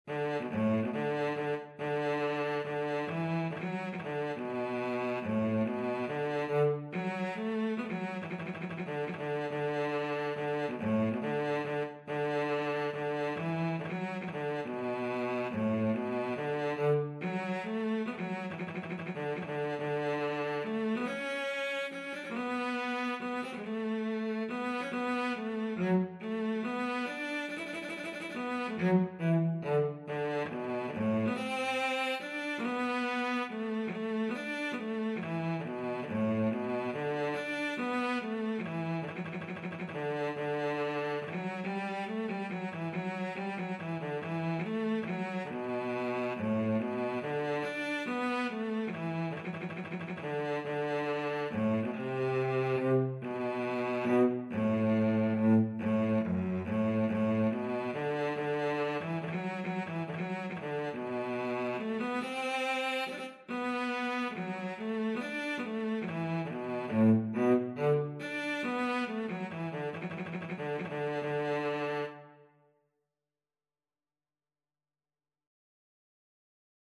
Cello version
D major (Sounding Pitch) (View more D major Music for Cello )
3/4 (View more 3/4 Music)
F#3-D5
Cello  (View more Easy Cello Music)
Traditional (View more Traditional Cello Music)